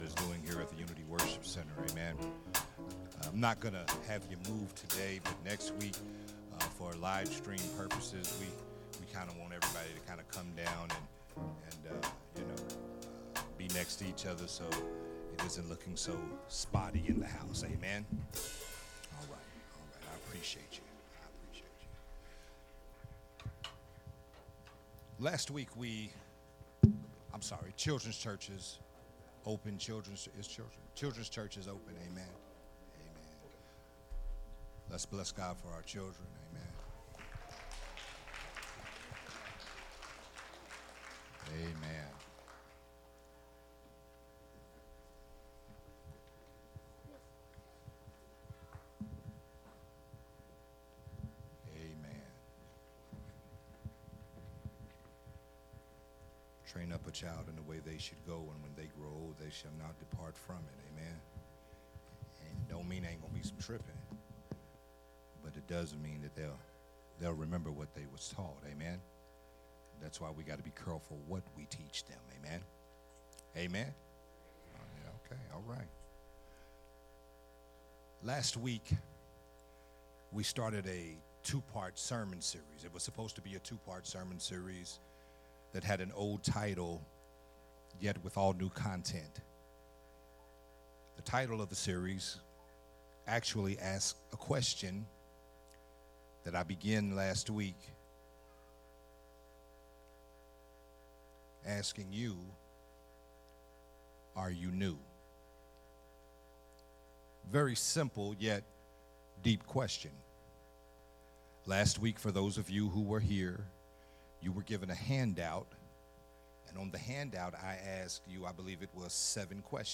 Sunday Morning message
Sunday Morning Worship Service